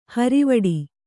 ♪ harivaḍi